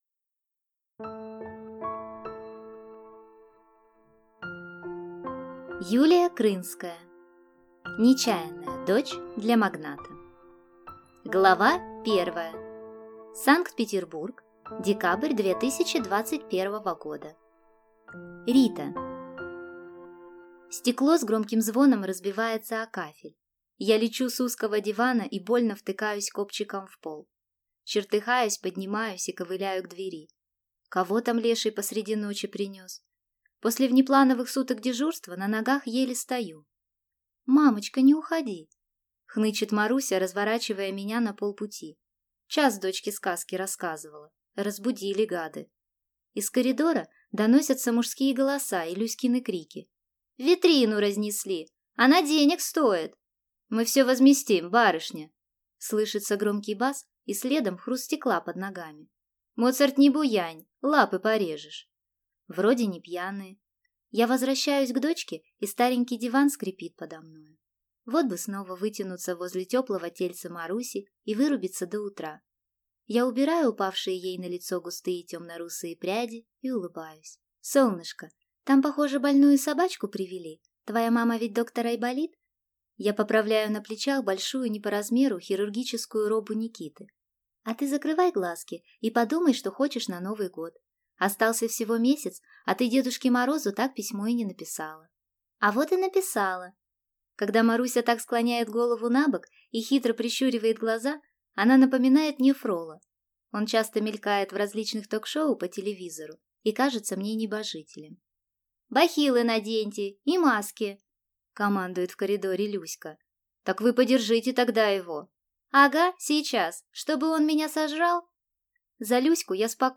Аудиокнига (Не)Чаянная дочь для магната | Библиотека аудиокниг